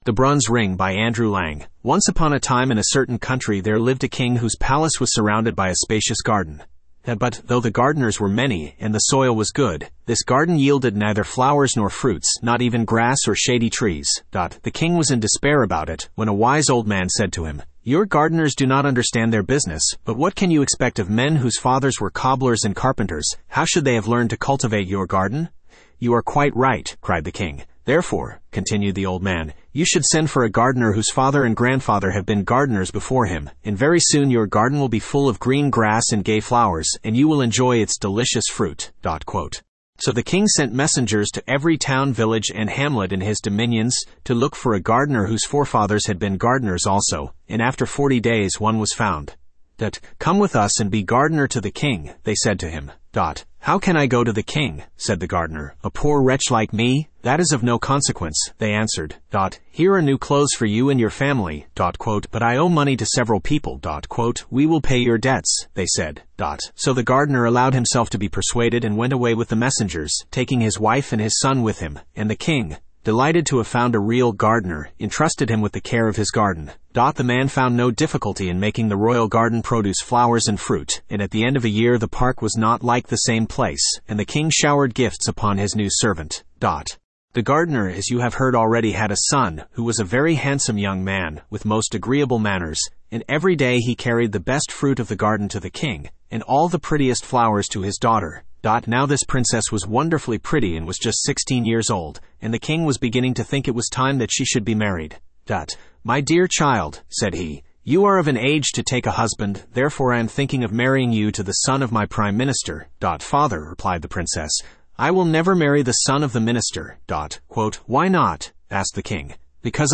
Spoken Ink « The Blue Fairy Book The Bronze Ring Studio (Male) Download MP3 Once upon a time in a certain country there lived a king whose palace was surrounded by a spacious garden.